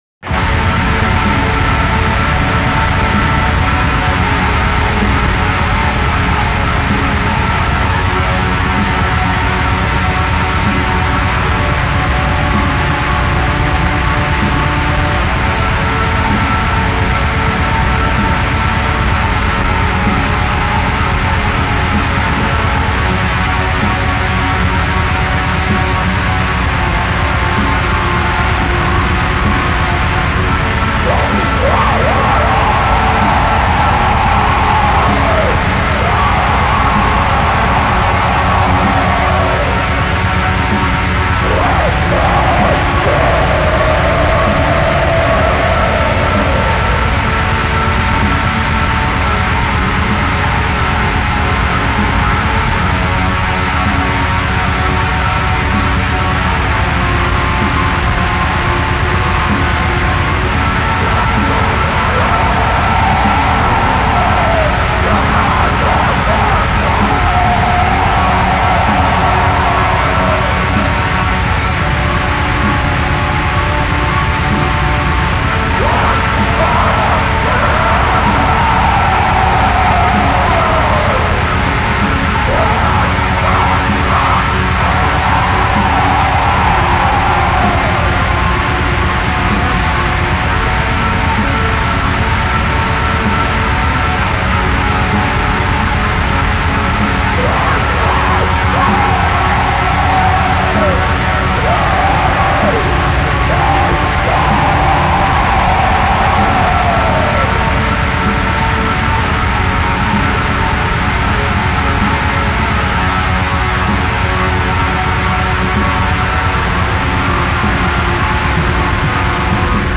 Depressive suicidal black metal
Ambient Black Metal
Depressive Black Metal
چرا ووکالش با افکت نویز شدید همراهه؟